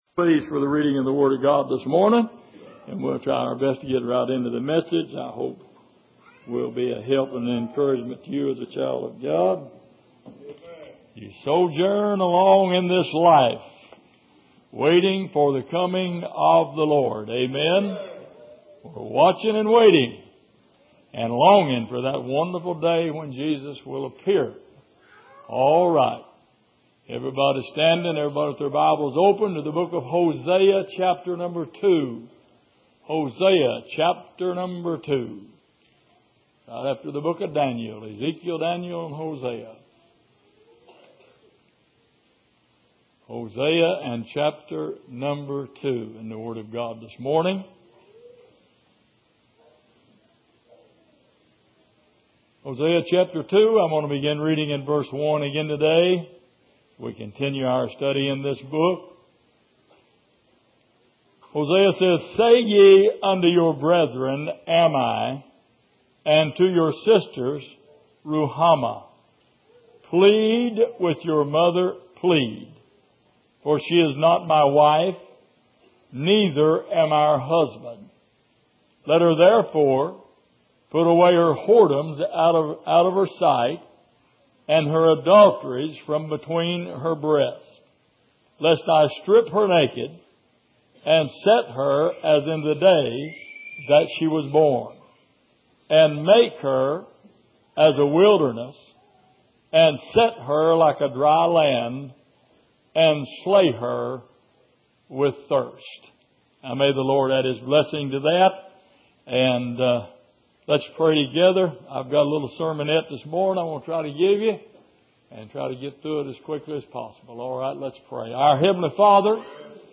Passage: Hosea 2:1-3 Service: Sunday Morning